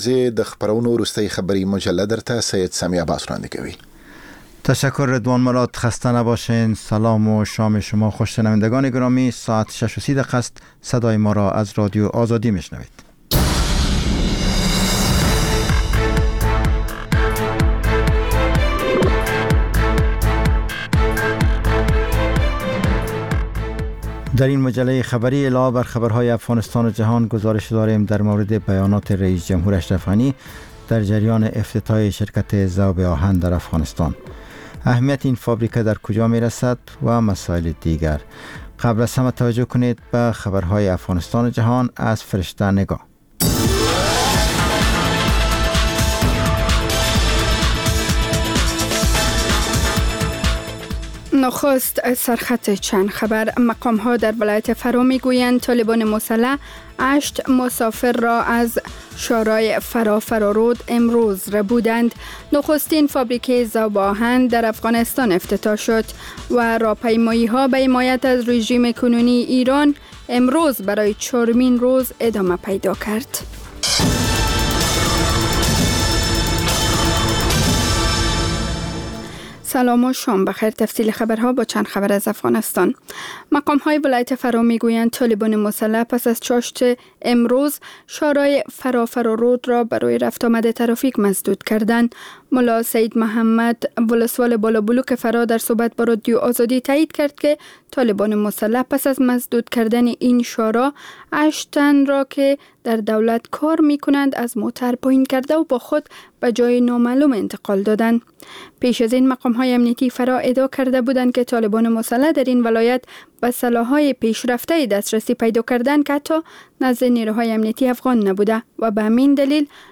مجله خبری شامگاهی